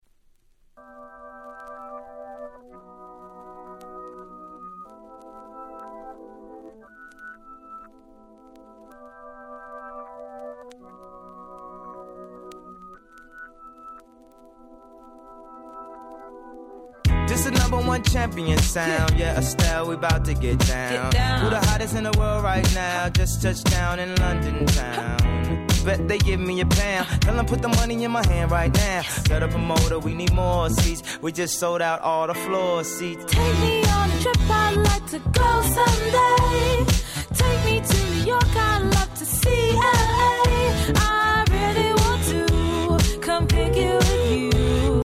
07' Smash Hit R&B !!
R&Bと言うよりかDisco調の曲調でHouse畑のDJやDisco畑のDJに渡り広く人気の1曲！